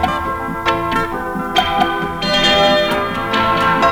AMBIENLOOP-L.wav